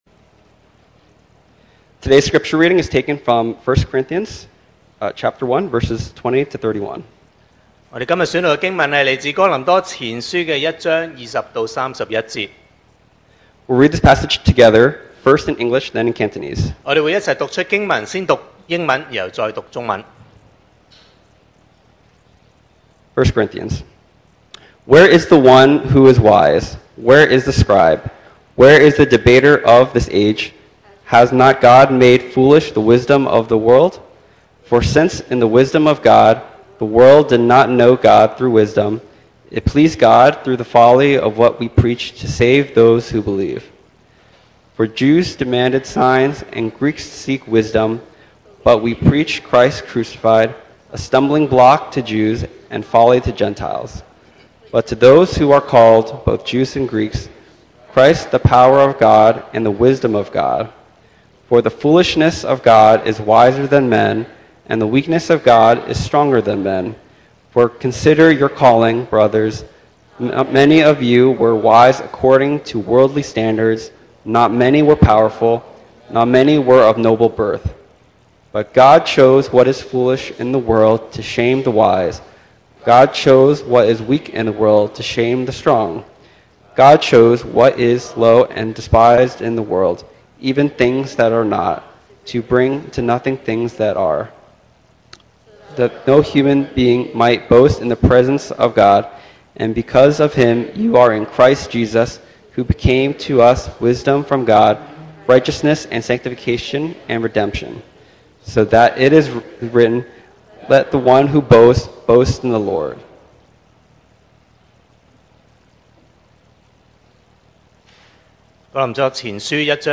2025 sermon audios
Service Type: Sunday Morning